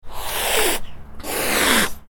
دانلود صدای گربه عصبانی و خشمگین آماده حمله از ساعد نیوز با لینک مستقیم و کیفیت بالا
جلوه های صوتی